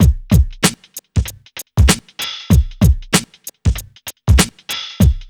2RB96BEAT1-L.wav